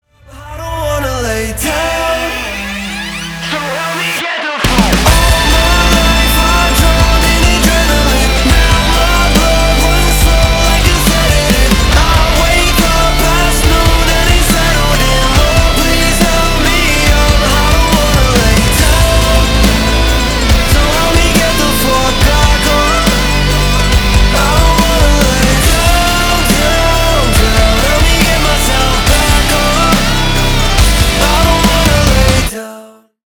• Качество: 320, Stereo
мужской вокал
громкие
Драйвовые
Alternative Rock